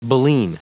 Prononciation du mot baleen en anglais (fichier audio)
Prononciation du mot : baleen